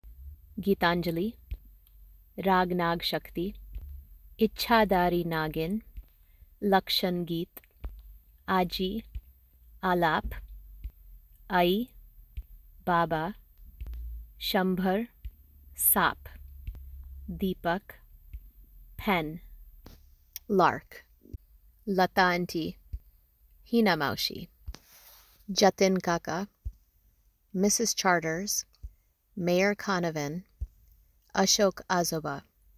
AHIMSA (uh-HIM-saa or uh-HIN-saa)
Anjali (UN-juh-lee)
Mohan (MO-hun)
the-cobras-song-pronounciations.m4a